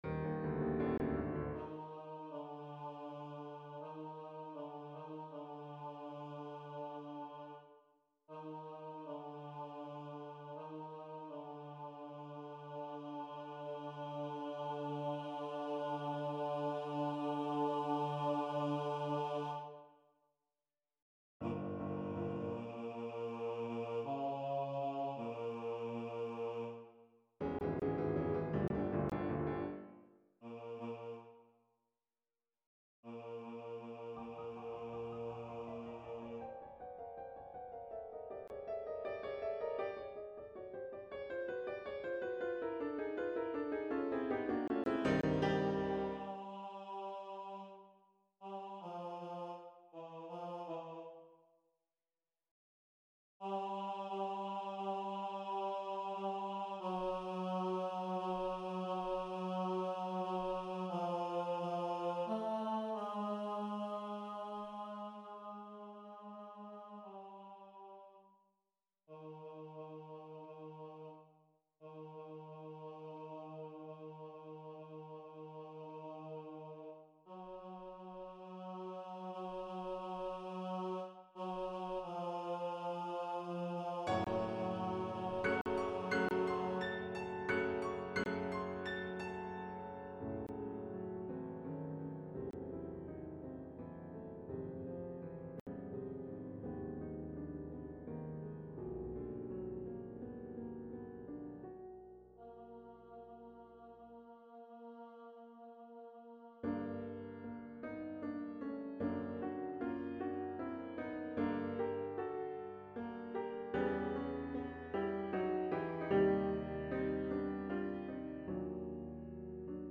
VAB-baritone-voice-only-1 Feb 9-18.mp3
Coastal Voices Men's Choir
vab-baritone-voice-only-1-feb-9-18.mp3